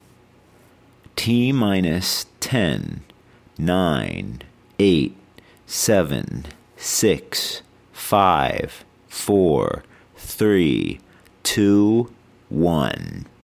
描述：与科幻相关的口头文本样本。
Tag: 语音 英语 科幻 美国航空航天局 电火花 声乐 口语 空间